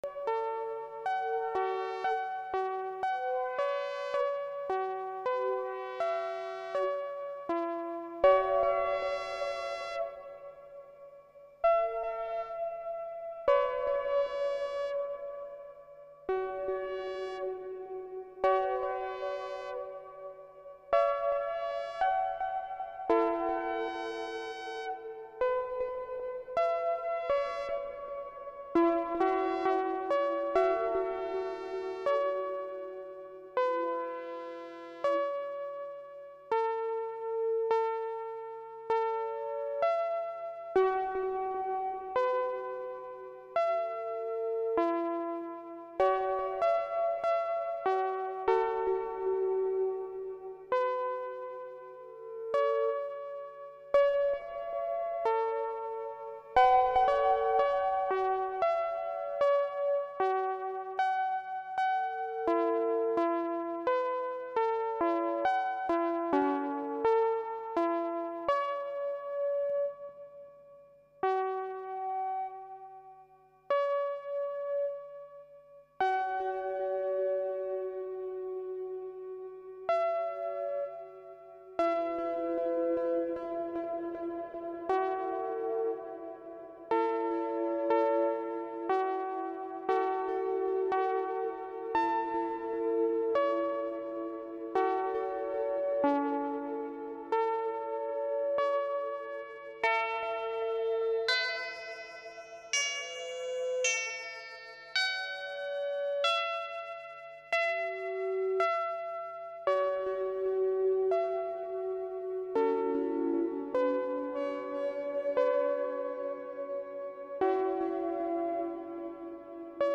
I think one of the oscillators is a bit out of tune here.